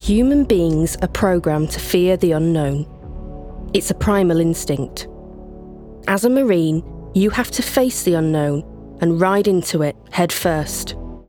Northern
Powerful, Confident, Dramatic, Informative